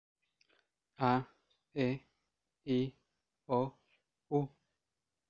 描述：Voz masculina23años。
Tag: 声乐 雄性 语音